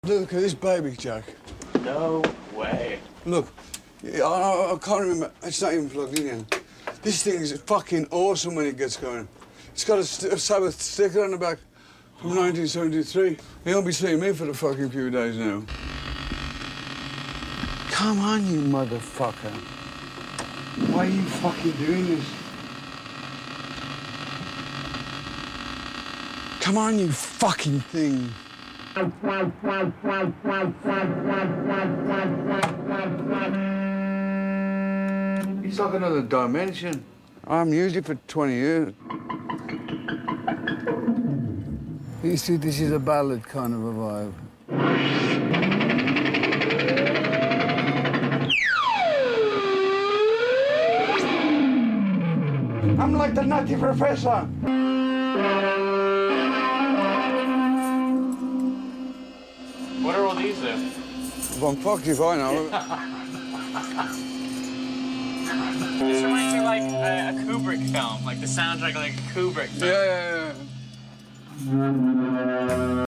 ARP 2600